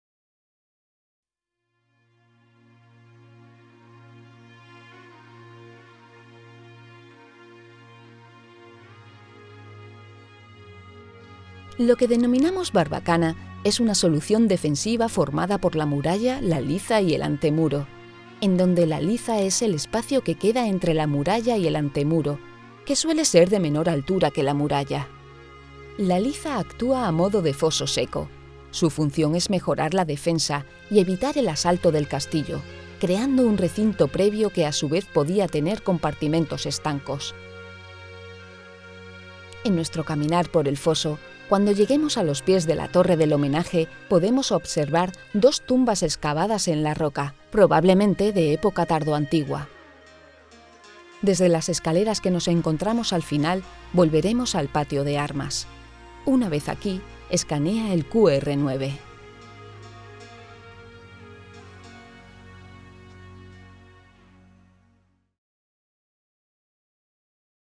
Ruta audioguiada